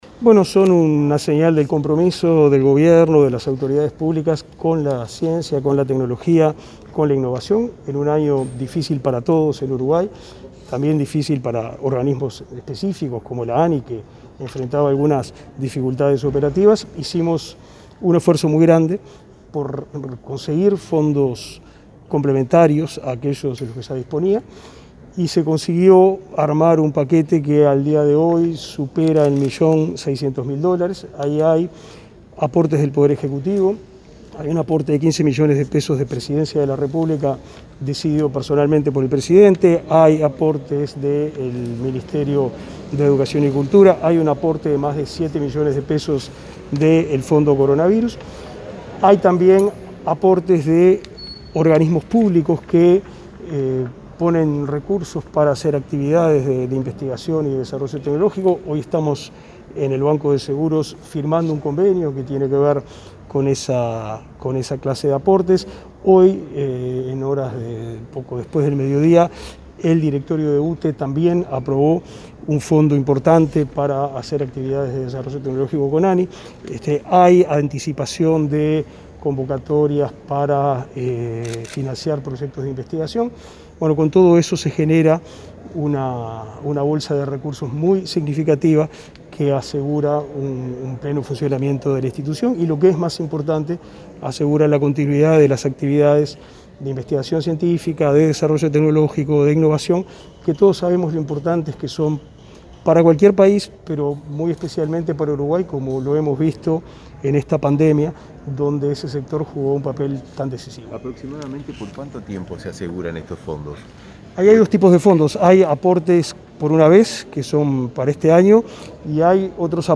Declaraciones a la prensa del ministro de Educación, Pablo da Silveira, y el presidente del BSE, José Amorín Batlle